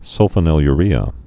(sŭlfə-nĭl-y-rēə)